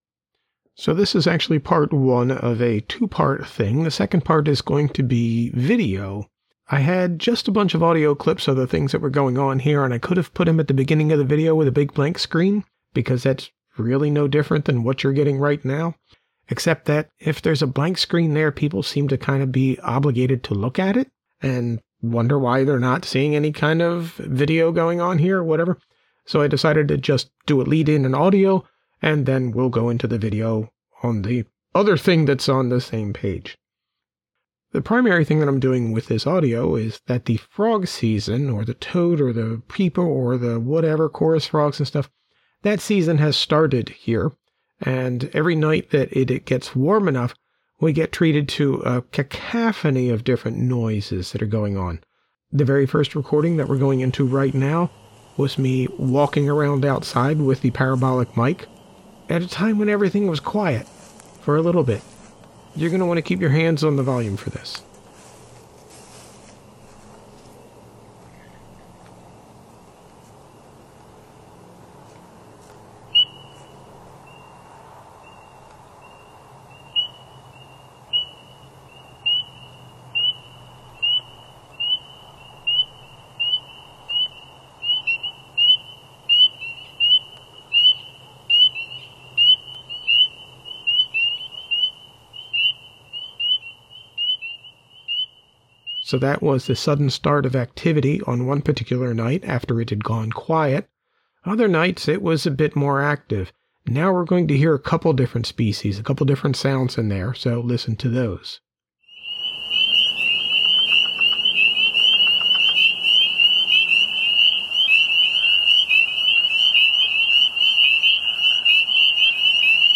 But there were several varieties of sounds, and I was unable to place all of them, or really most of them.
So we’ll start with what was obtained with the audio recorder and the parabolic mic, but I will strongly suggest you keep one hand on the volume control – the peaks are sharp.
Amphibious calls early spring 2026
I failed to remark on it within, but it seems damn near every bit of audio I snag at night has a distant train – I never realized how often they can be heard, always several kilometers off, but it’s a lot.
peepersnfriends.mp3